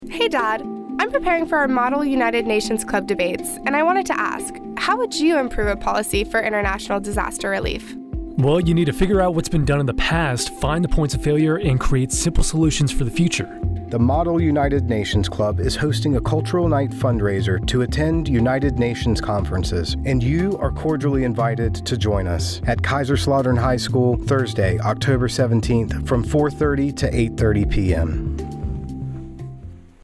Radio Spot - Model United Nations Cultural Night